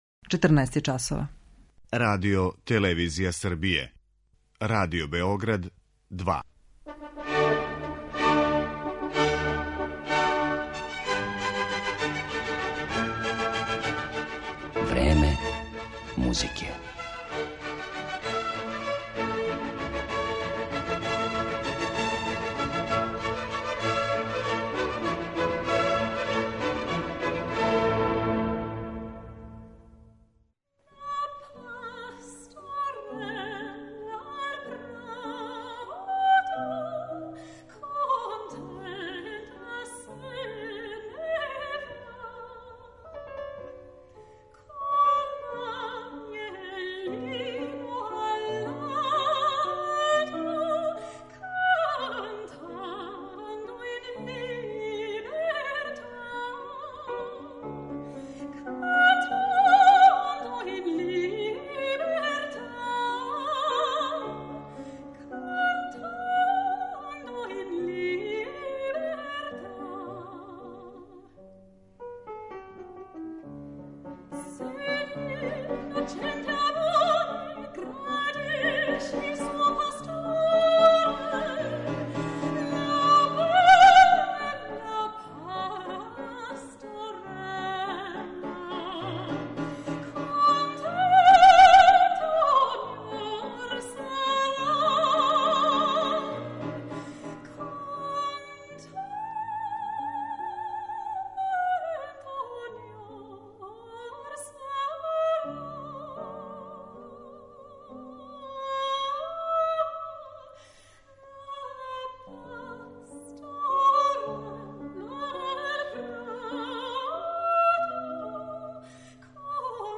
Композиције Августа Хербинга, Карла Филипа Емануела Баха, Фридриха Флајшера, Кристијана Волфа и Волфганга Амадеуса Моцарта изводиће једна од најатрактивнијих вокалних солисткиња данашњице, британски сопран Керолајн Семпсон.